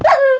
sounds / mob / wolf / hurt3.ogg
hurt3.ogg